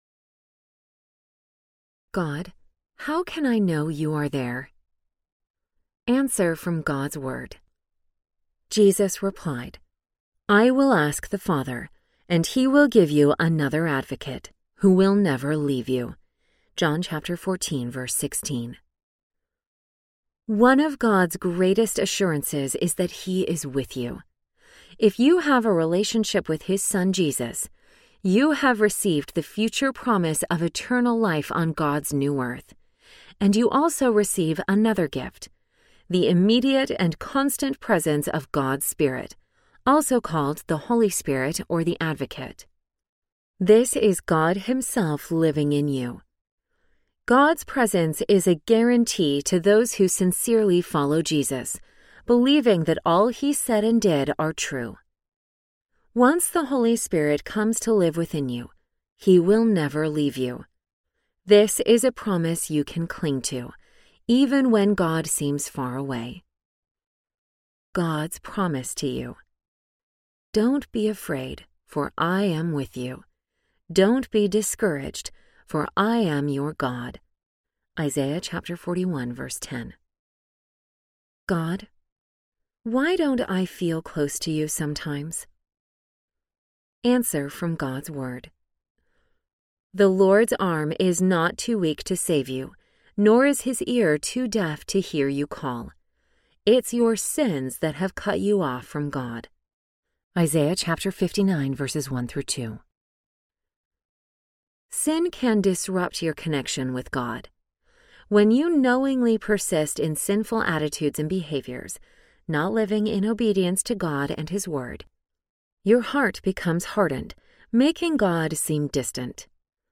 God’s Little Book of Answers to Big Questions Audiobook
Narrator
3.2 Hrs. – Unabridged